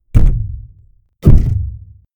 hunter_statue_thud.4.ogg